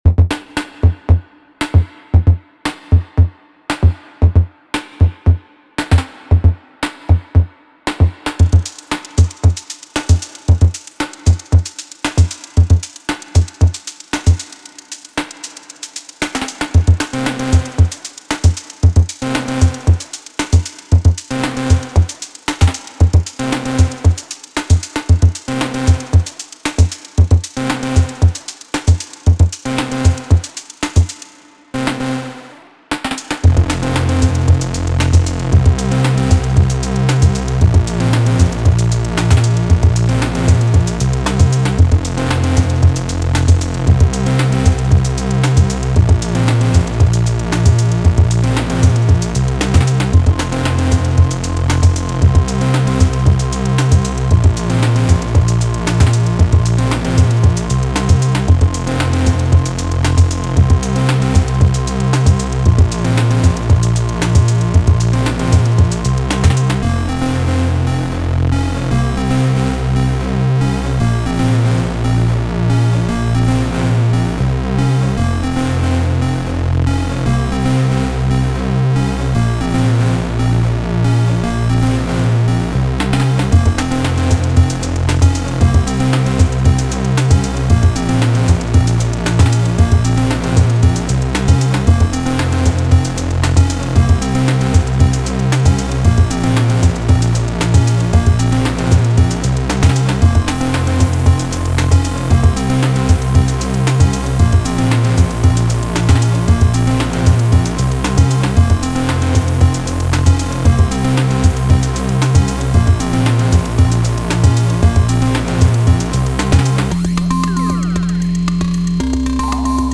I remember that making a track with Sy Bits only, was one of the first things I did when I got my ST in 2022.(never did it again though)